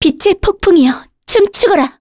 Talisha-Vox_Skill7_kr.wav